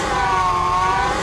droid_fly.wav